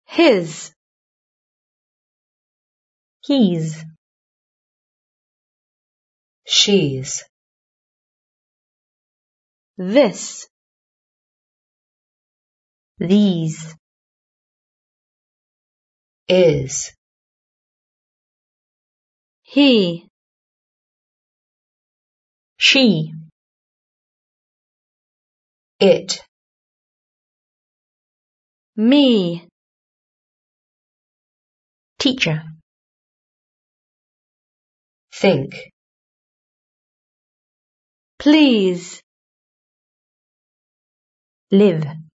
Listen. Do these words have a short or long sound? Click and drag the words to the correct box.